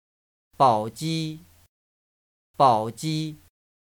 Baoji (simplified Chinese: 宝鸡; traditional Chinese: 寶雞; pinyin: Bǎojī; Mandarin pronunciation: [pàʊ.tɕí]
Bao3ji1.ogg.mp3